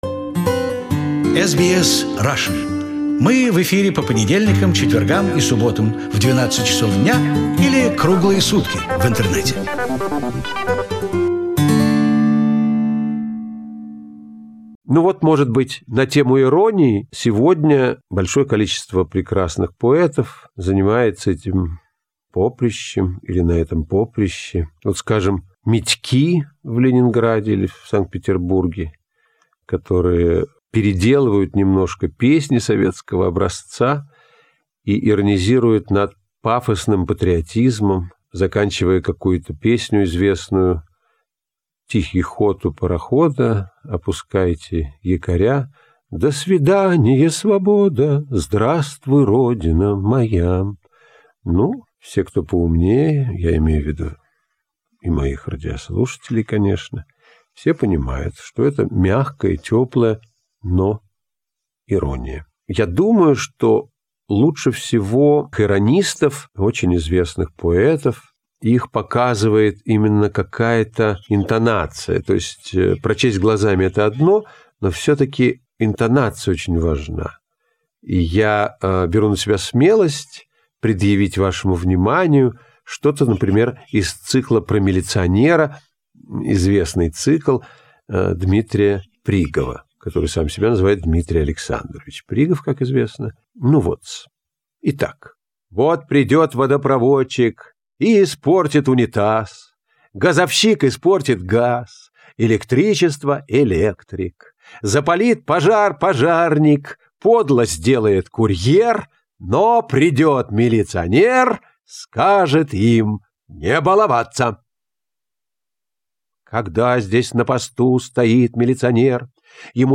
His concerts were a big success in Australia, and we asked him to record some of his material for the radio. Fortunately the recordings survived and we are happy to preserve them on our web archives In this recording Veniamin Smekhov talks about poetry, known as Ironism by the late contemporary conceptual artist and poet Dmitri Prigov